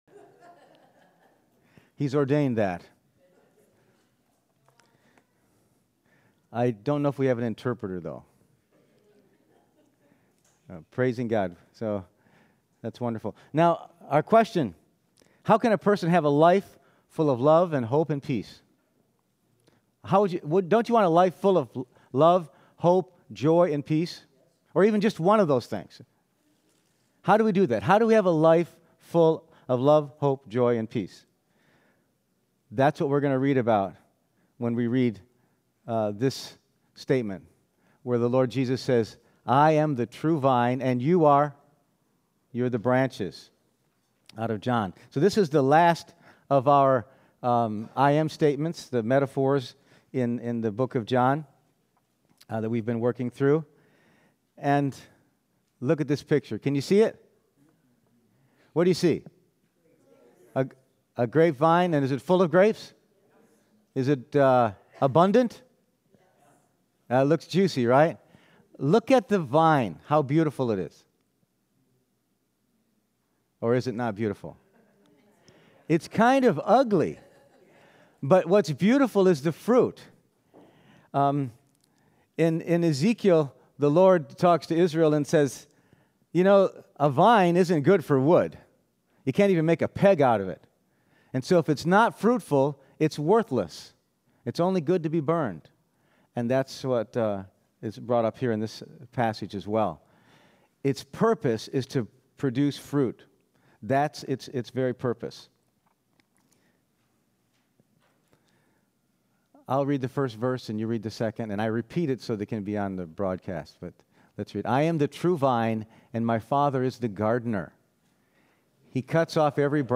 Passage: John 15:1, John 15:5 Service Type: Sunday Morning